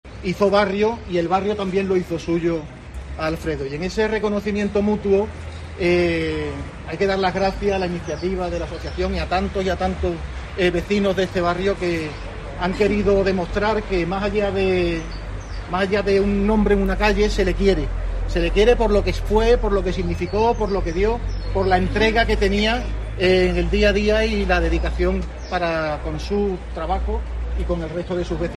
Demetrio Quirós, primer teniente de alcalde del Ayuntamiento de Cádiz